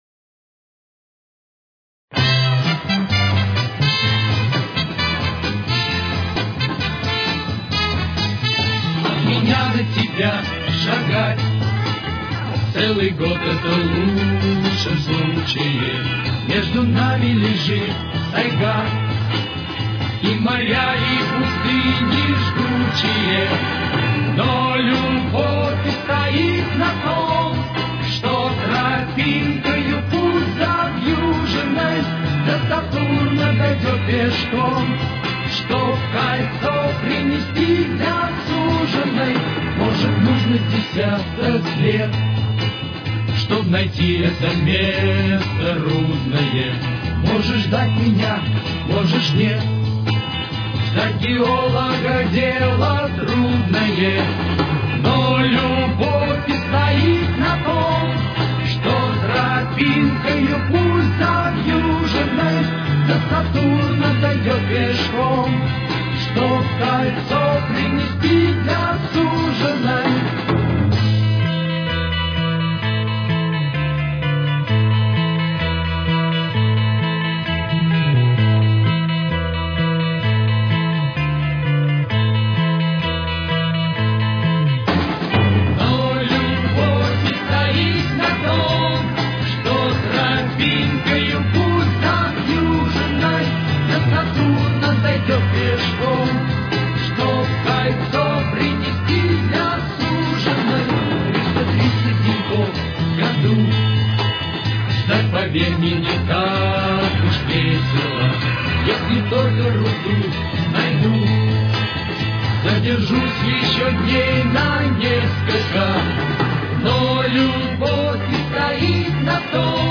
Тональность: Ля минор. Темп: 131.